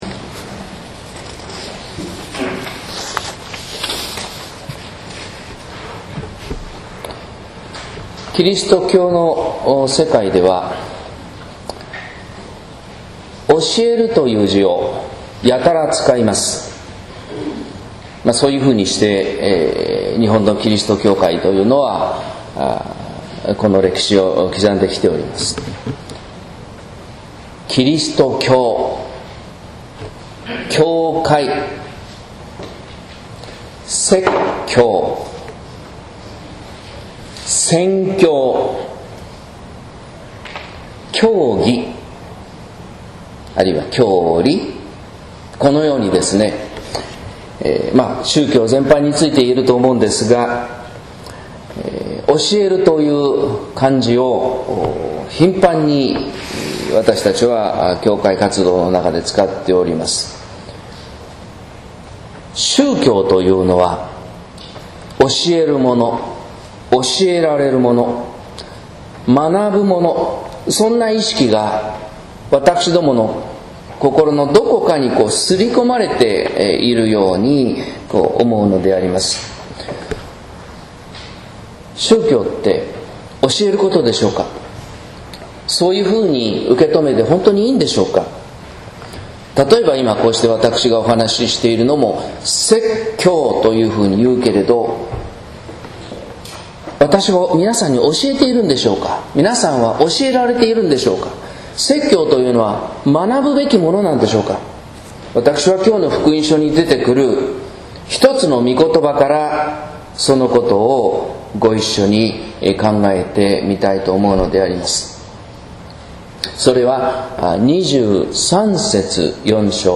説教「教える、否、宣べ伝える」（音声版）